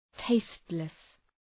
Προφορά
{‘teıstlıs}